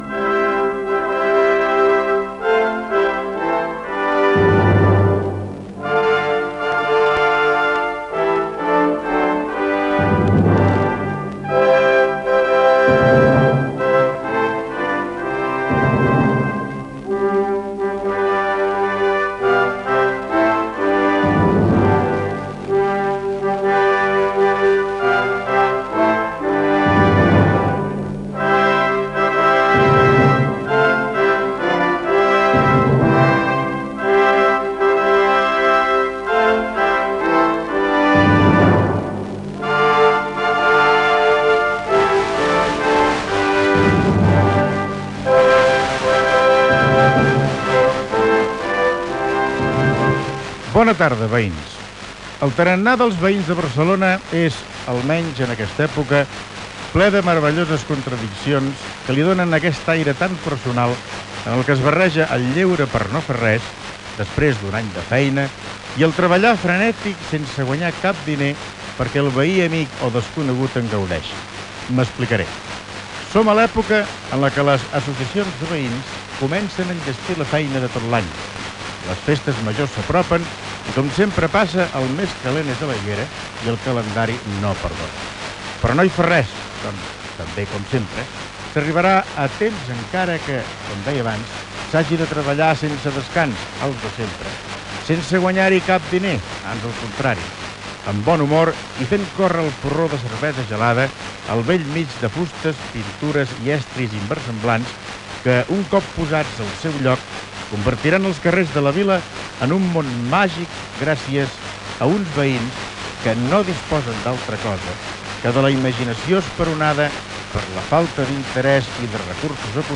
Sintonia, comentari sobre les associacions de veïns i les festes majors, tema musical, espai dedicat als duets còmics de les sarsueles
Gènere radiofònic Musical